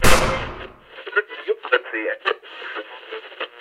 Gun Shot for Radio
Category: Sound FX   Right: Personal